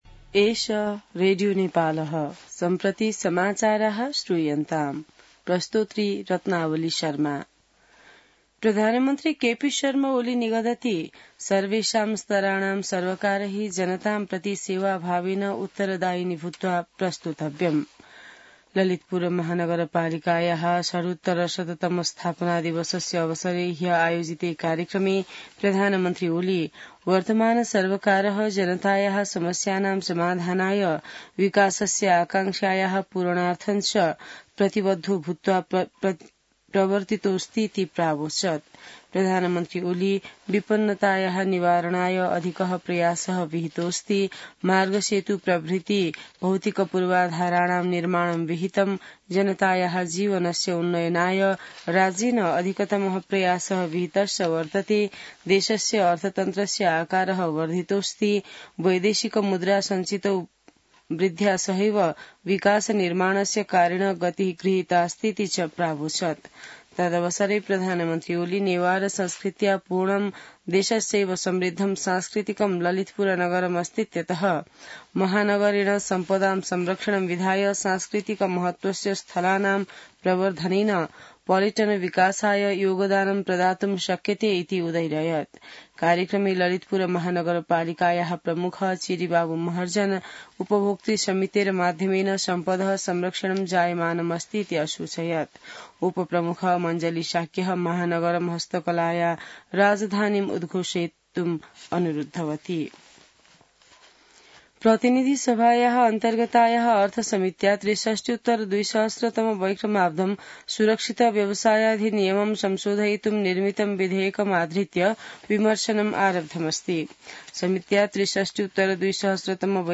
संस्कृत समाचार : ४ पुष , २०८१